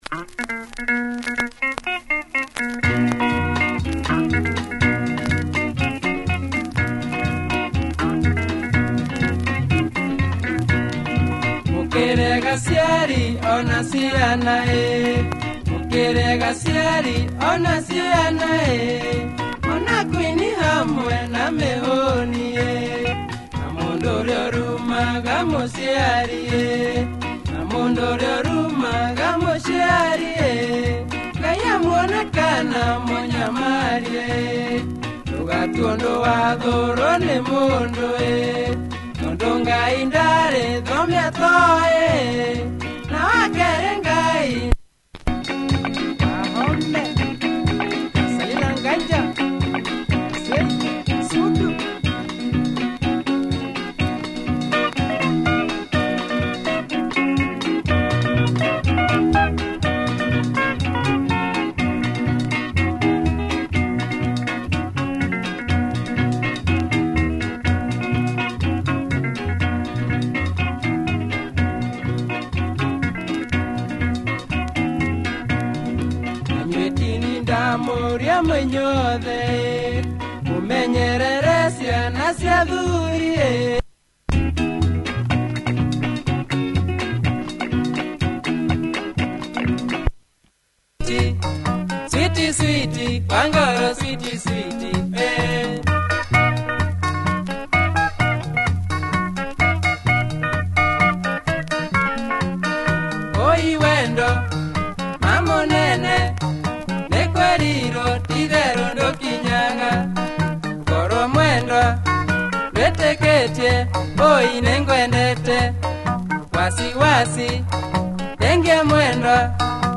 Nice kikuyu benga
Good beat and nice breakdown midway on both tracks.